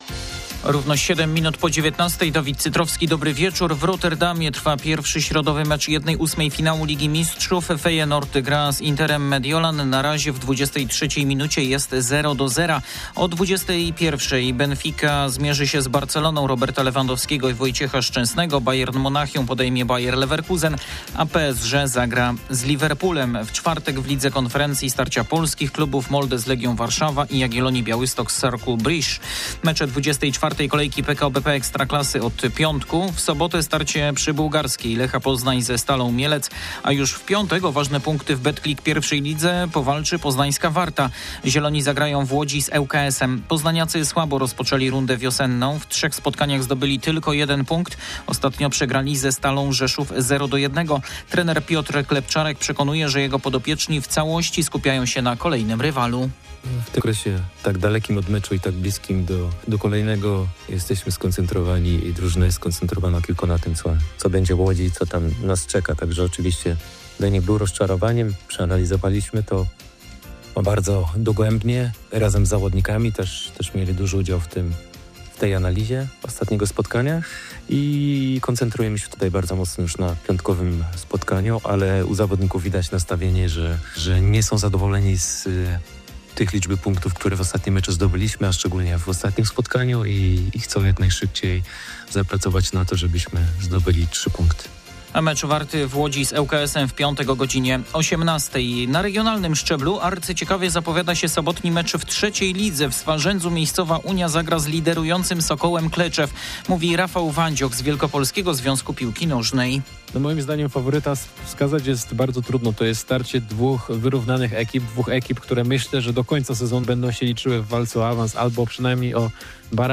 05.03.2025 SERWIS SPORTOWY GODZ. 19:05